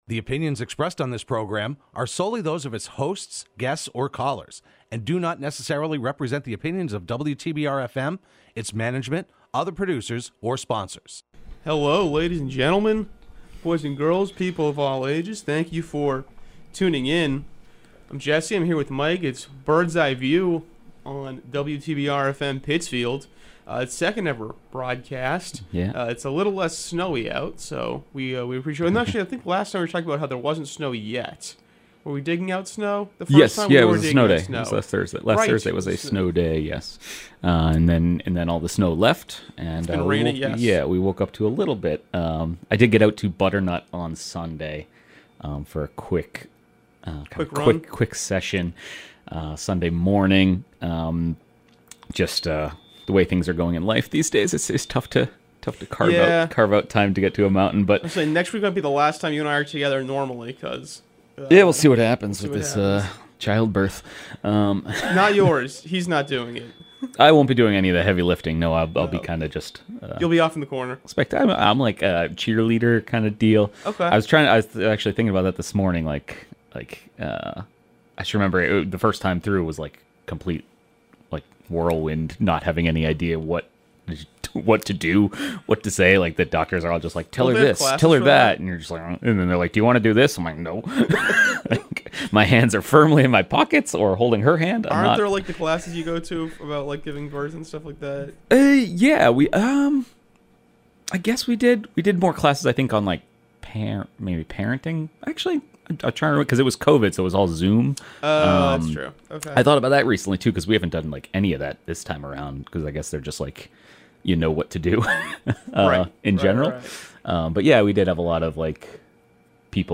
Hosted by the Berkshire Eagle Sports team and broadcast live every Thursday morning at 10am on WTBR.